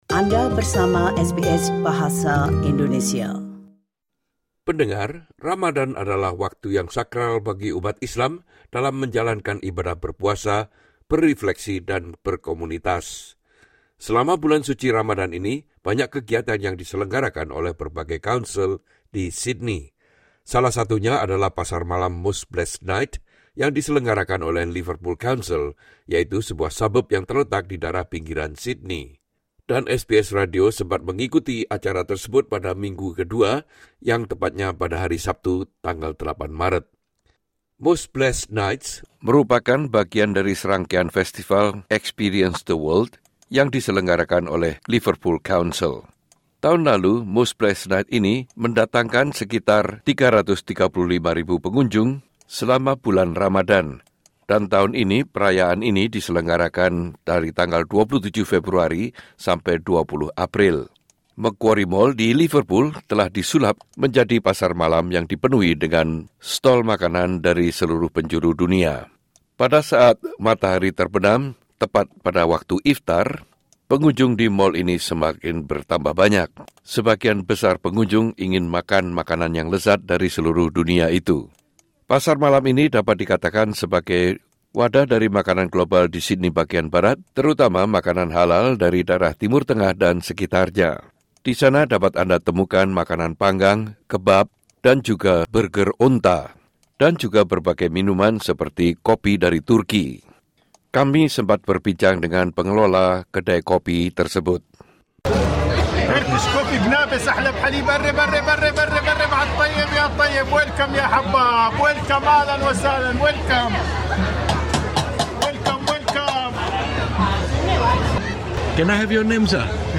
DENGARKAN Most Blessed Nights: Ramadan Malam di Liverpool SBS Indonesian 05:15 Indonesian SBS Radio sempat mengikuti keramaian ini pada minggu ke 2 tepatnya pada hari Sabtu 8 Maret.
Suasana pesta jalanan itu diramaikan oleh Para penabuh drum Lebanon yang membawakan irama Timur Tengah.